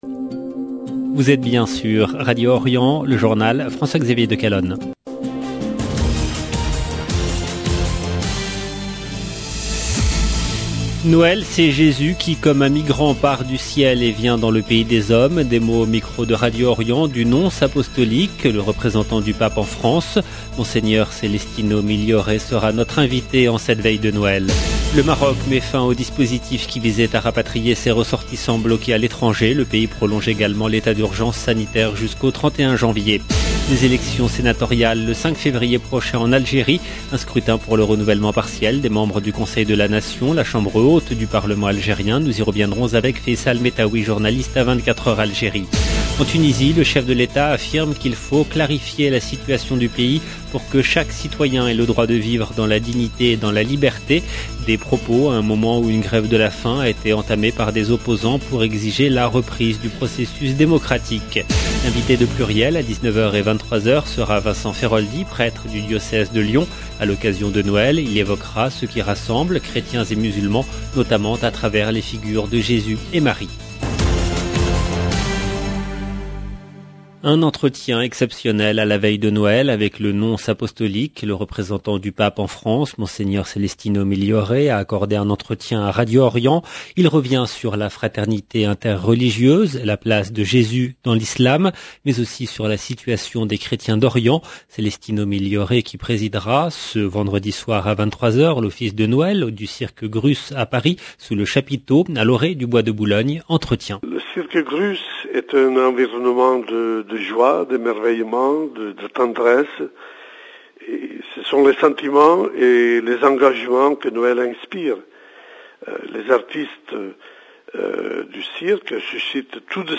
EDITION DU JOURNAL DU SOIR EN LANGUE FRANCAISE DU 24/12/2021
Monseigneur Celestino Migliore sera notre invité en cette veille de Noël. Le Maroc met fin au dispositif qui visait à rapatrier ses ressortissants bloqués à l'étranger.